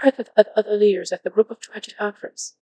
coqui-tts - a deep learning toolkit for Text-to-Speech, battle-tested in research and production